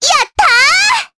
Cleo-Vox_Happy4_jp.wav